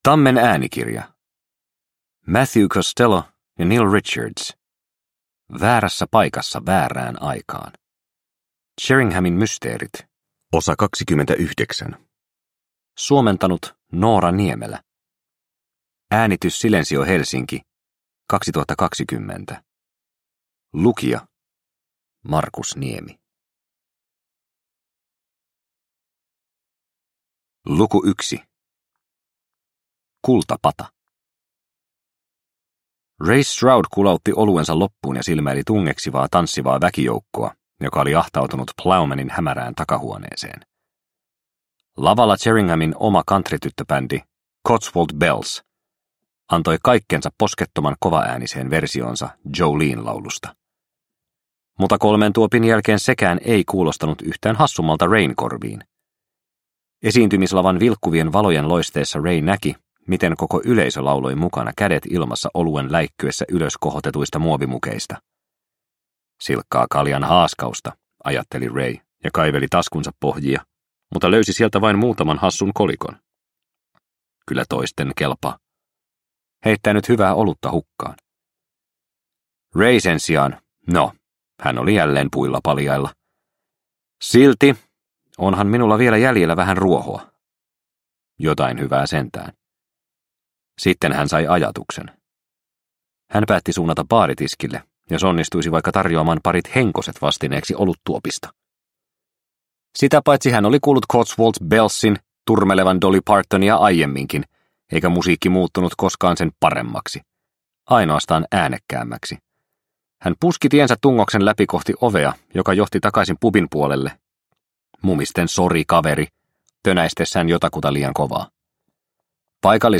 Väärässä paikassa väärään aikaan – Ljudbok – Laddas ner